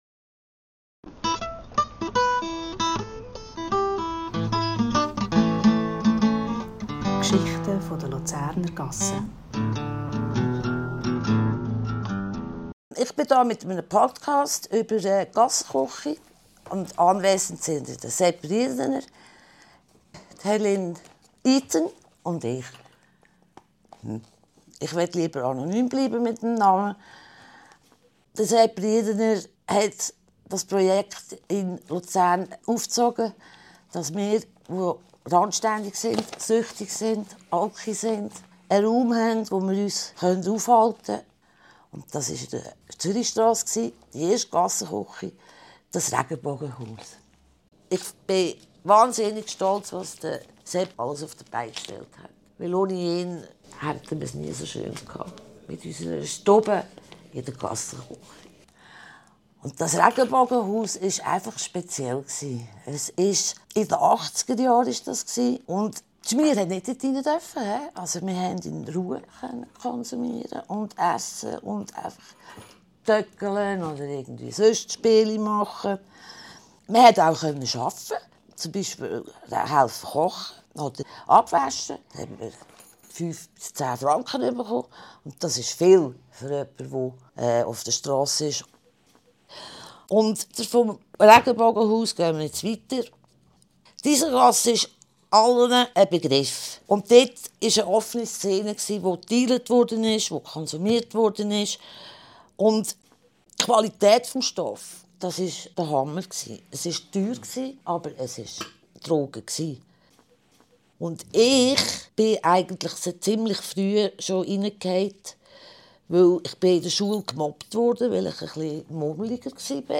/ aufgenommen in der Gassechuchi am 4. März 2026